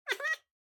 豹猫空闲时随机播这些音效
Minecraft_ocelot_idle3.mp3